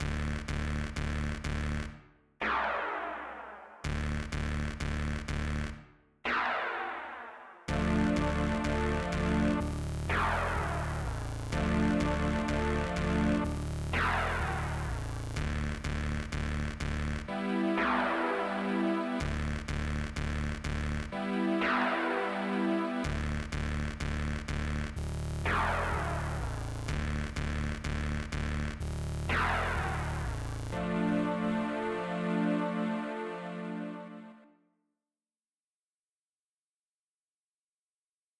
(A long slept hazy dream in an electronic landscape)